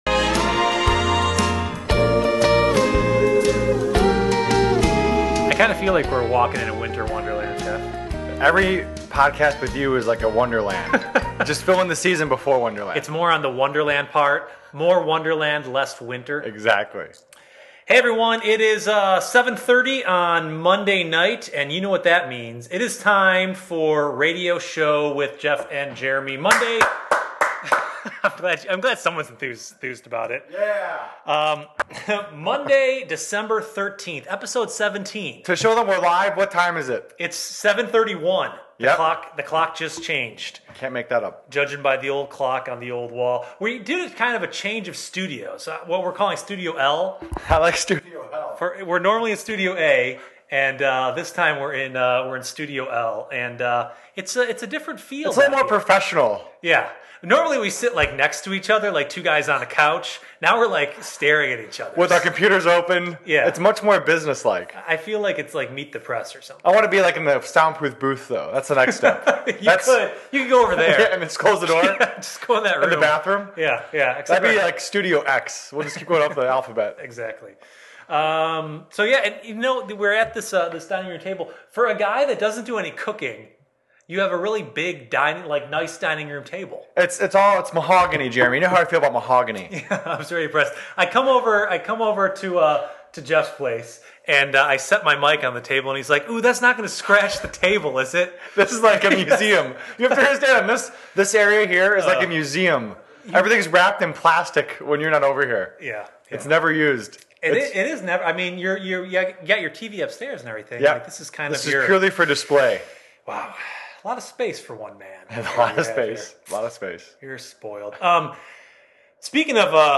Episode 17 – December 13, 2010 — Live from Studio L in the Tremont neighborhood of Cleveland, Ohio…